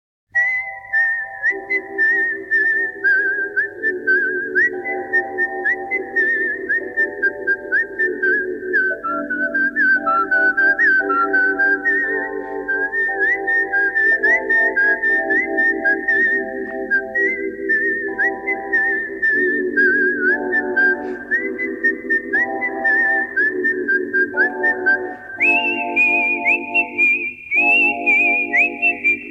• Качество: 192, Stereo
свист
Знаменитая мелодия с насвистыванием